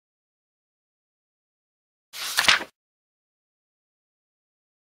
دانلود آهنگ ورق زدن کتاب 1 از افکت صوتی اشیاء
دانلود صدای ورق زدن کتاب 1 از ساعد نیوز با لینک مستقیم و کیفیت بالا
جلوه های صوتی